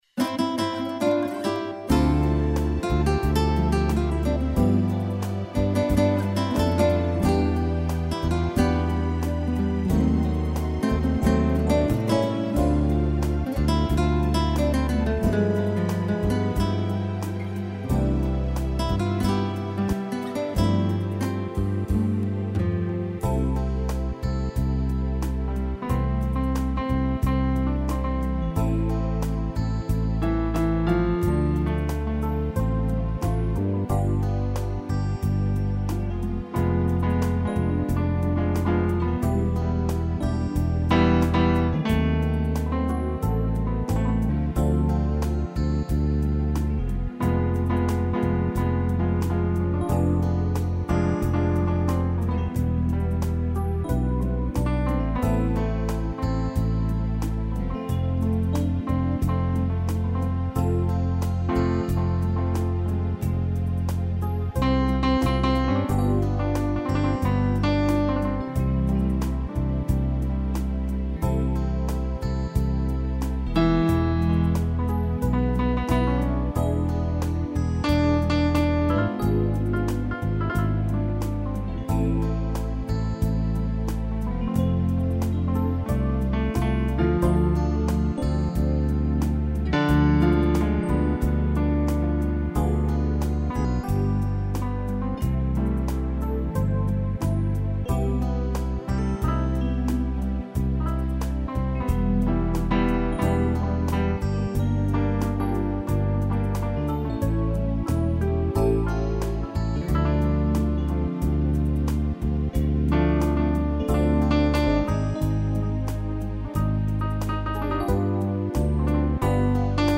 instrumental
interpretação teclado